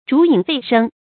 逐影吠聲 注音： ㄓㄨˊ ㄧㄥˇ ㄈㄟˋ ㄕㄥ 讀音讀法： 意思解釋： 形容人云亦云，毫無主見。